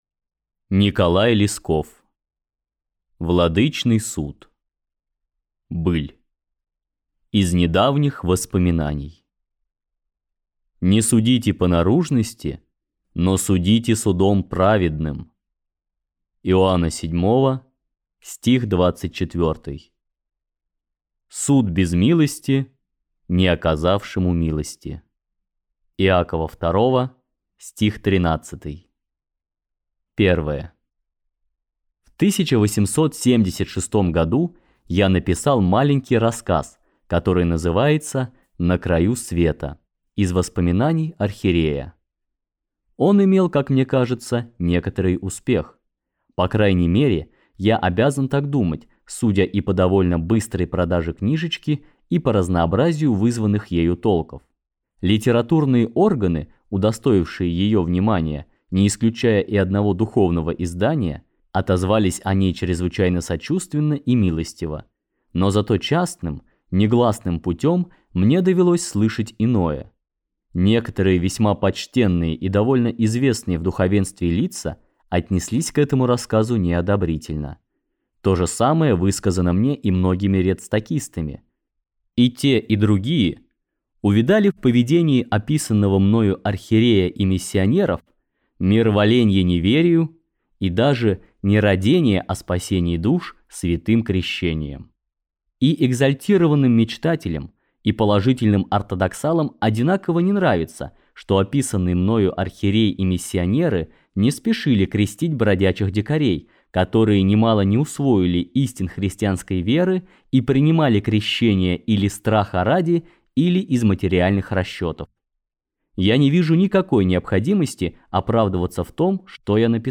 Аудиокнига Владычный суд | Библиотека аудиокниг